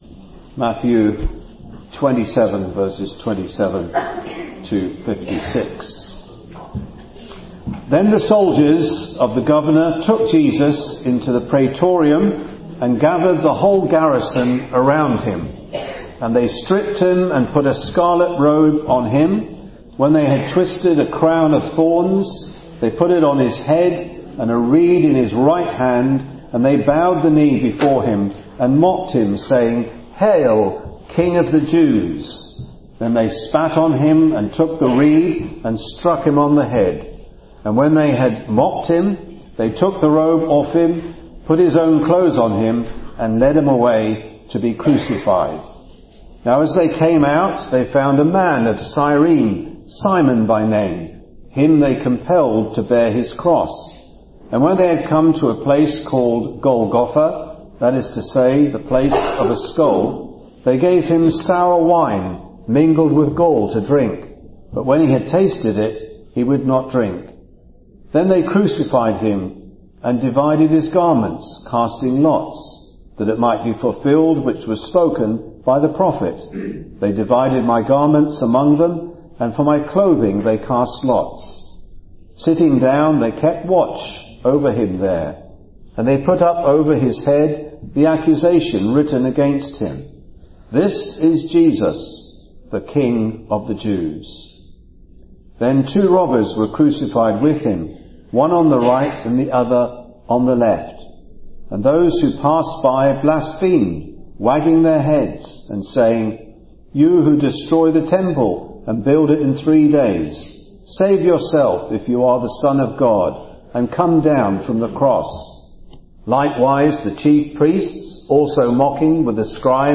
Audio recordings and transcripts of Bible messages shared at OIC.